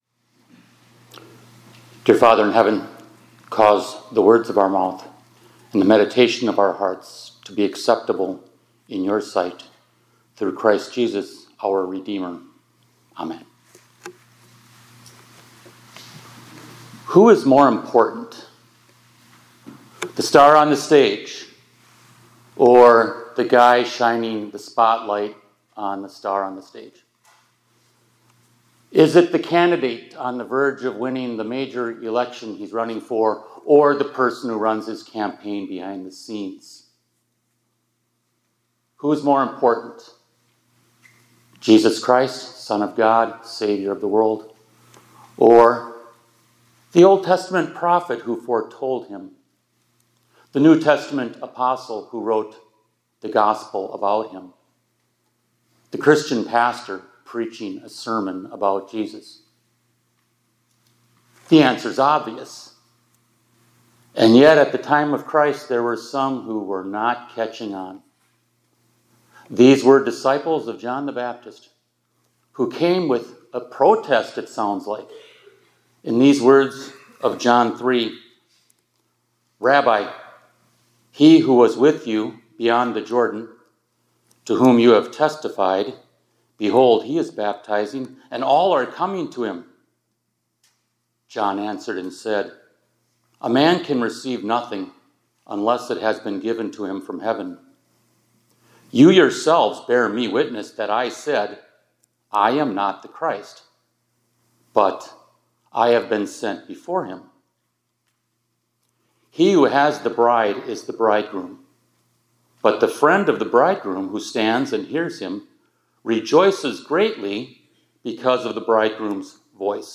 2025 Chapels -